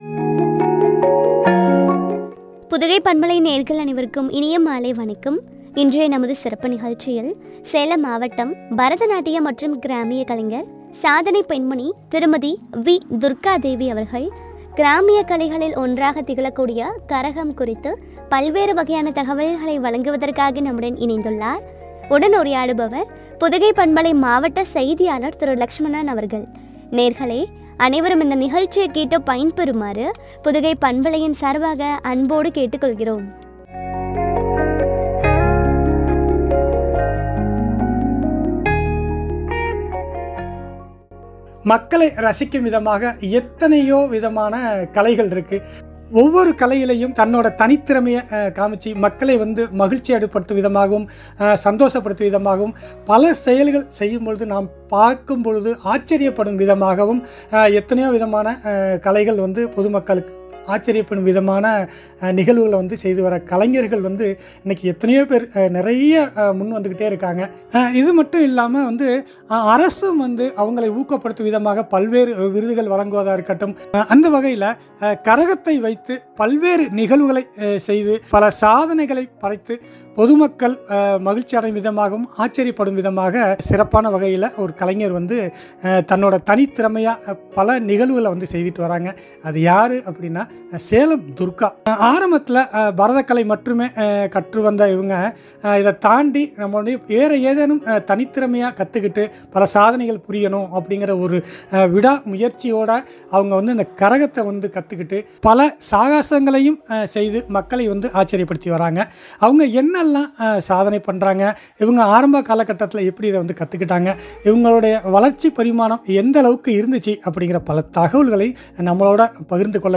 சாதனை பெண்மணி குறித்து வழங்கிய உரையாடல்.